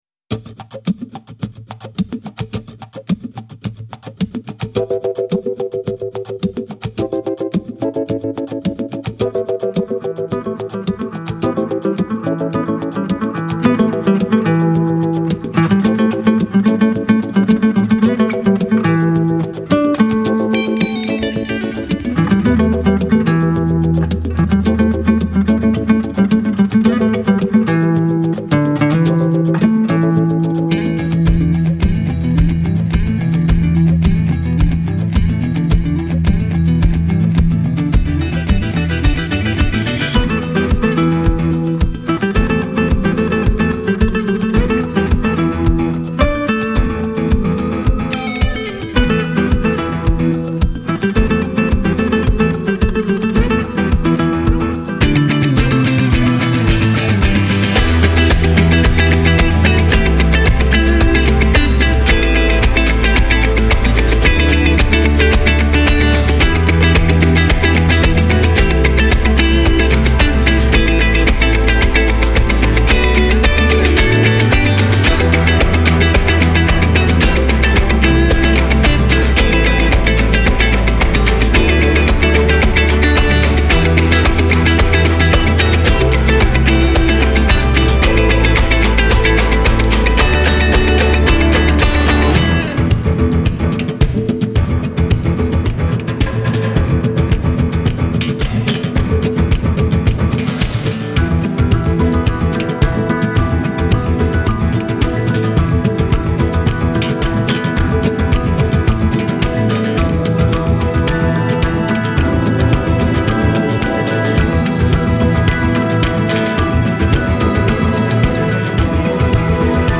Genre : Latin